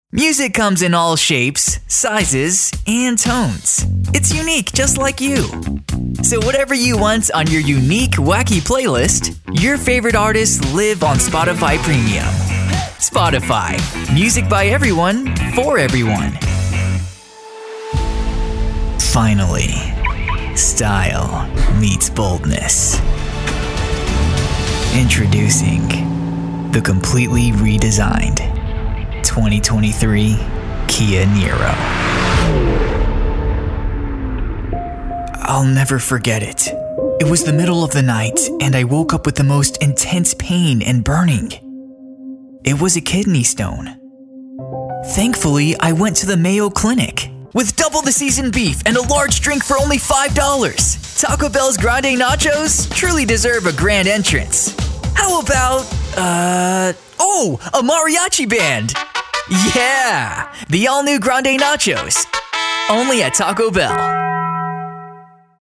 Warm Conversational E Learning Demo